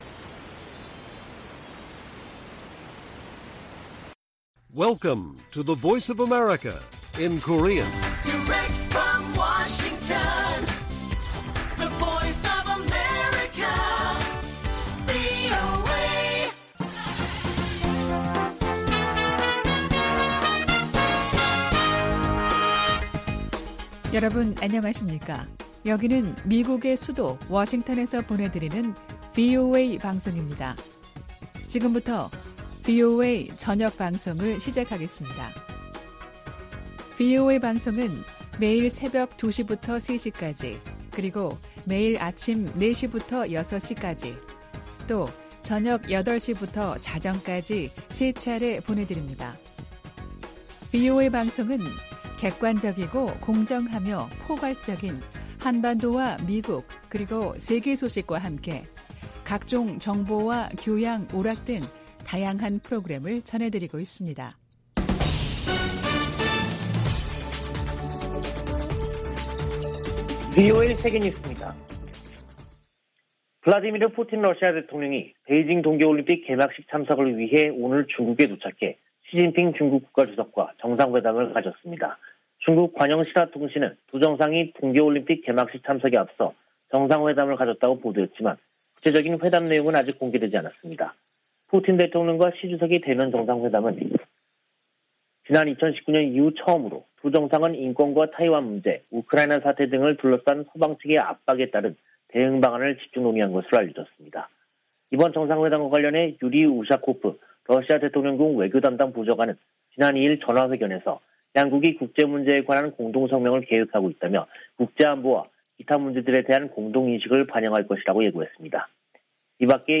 VOA 한국어 간판 뉴스 프로그램 '뉴스 투데이', 2022년 2월 4일 1부 방송입니다. 미 국무부는 북한의 탄도미사일 발사에 대응해 동맹과 파트너는 물론, 유엔과 협력하고 있다고 밝혔습니다. 미군 당국이 일본·호주와 실시 중인 연합 공중훈련의 목적을 설명하면서 북한을 거론했습니다. 호주가 북한의 최근 잇단 미사일 발사를 규탄하며 대량살상무기와 탄도미사일 추구는 국제 평화와 안보에 중대한 위협이라고 지적했습니다.